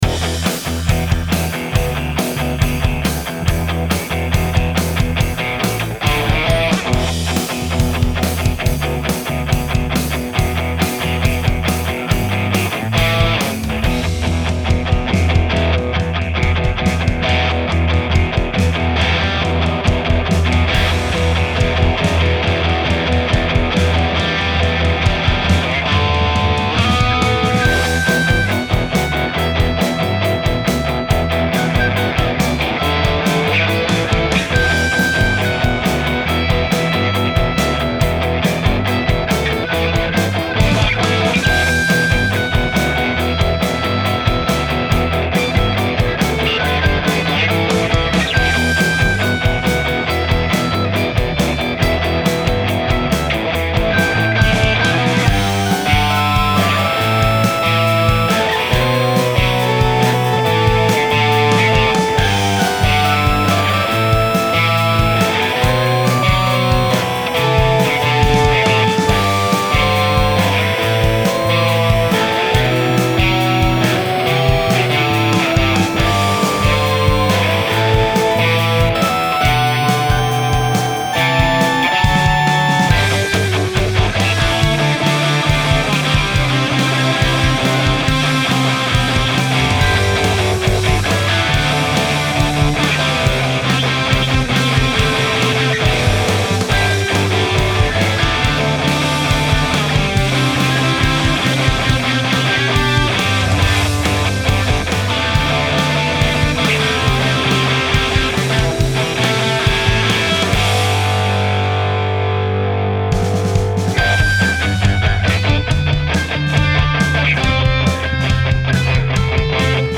3) "Our American Back Yard" - I really like this; good guitaring and grooving. Even with the off timing of the melody.